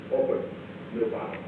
Secret White House Tapes
Conversation No. 442-57
Location: Executive Office Building
The President met with an unknown person.